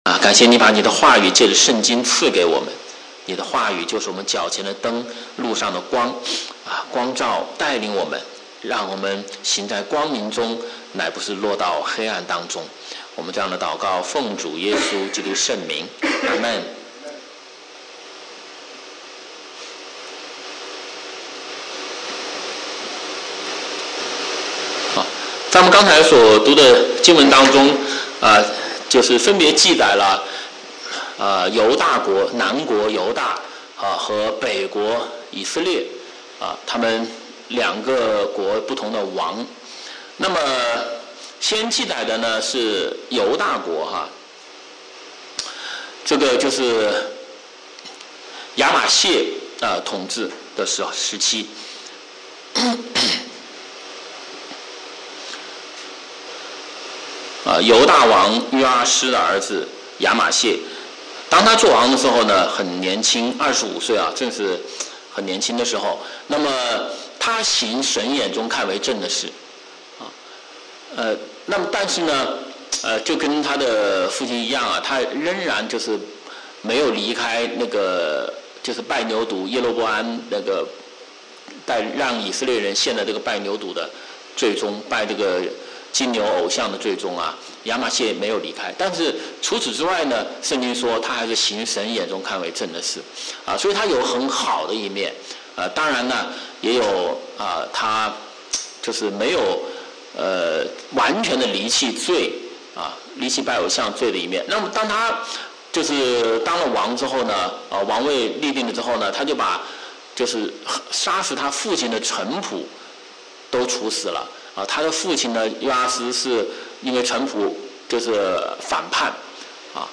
周五晚上查经讲道录音